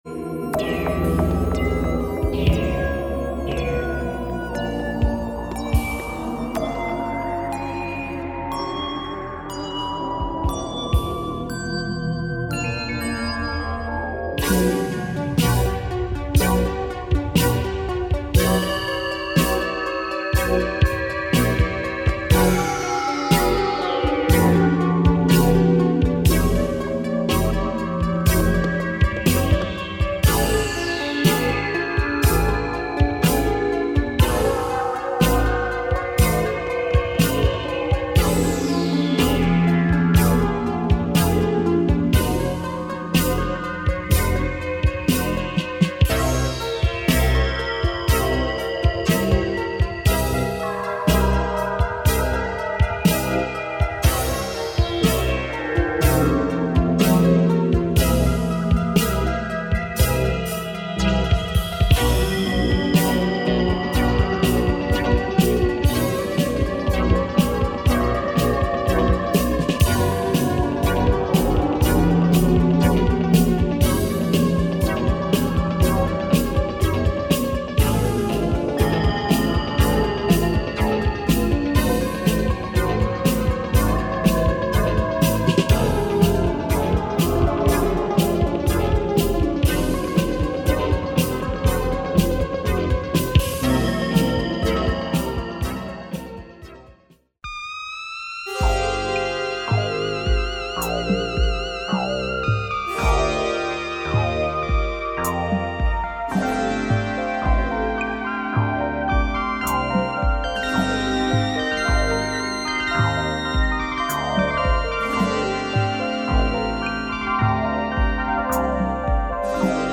Dope Baltic psych prog groove with heavy samples !